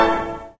receive-error.5976e33a.ogg